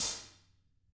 Index of /90_sSampleCDs/ILIO - Double Platinum Drums 1/CD2/Partition D/THIN A HATR